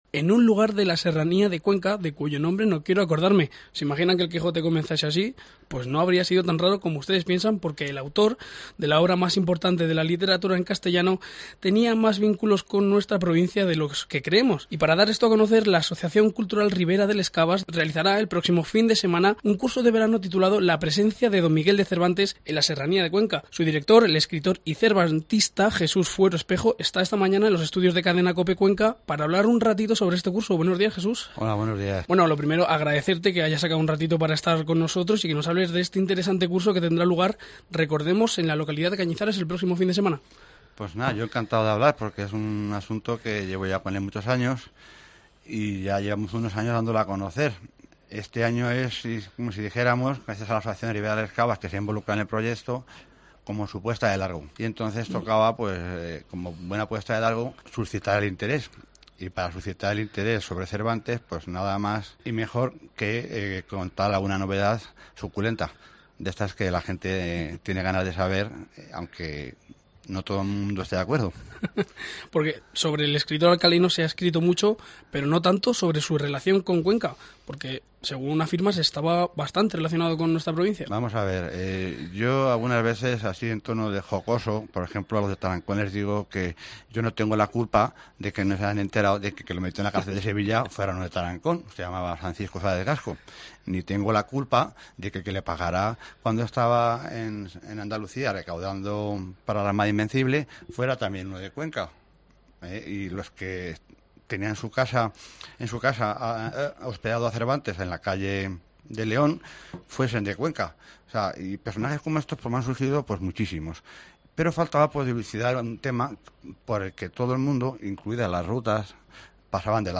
se hacerca a los micrófonos de COPE para contarnos la relevancia del viaje de Cervantes por la Serranía.